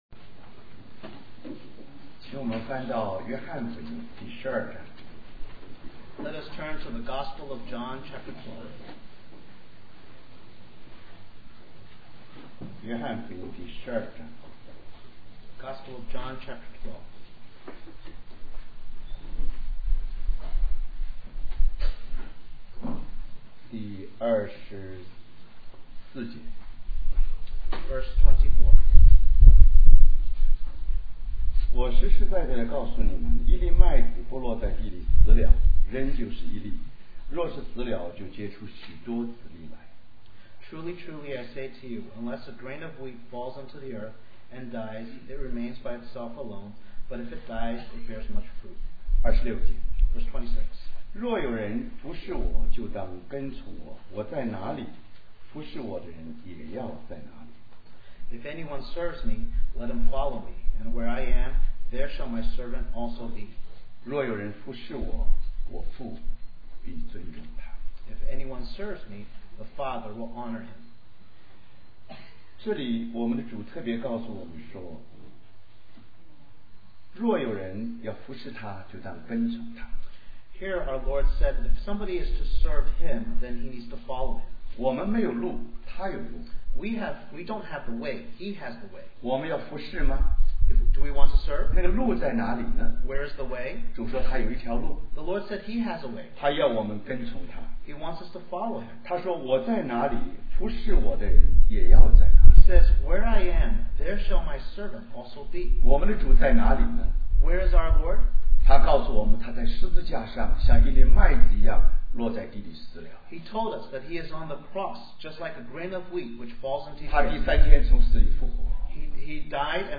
Special Conference For Service, Australia